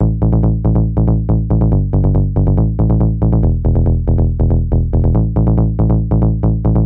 肥沃的基础线
描述：非常肥大的舞蹈低音
Tag: 140 bpm Dance Loops Bass Loops 1.15 MB wav Key : Unknown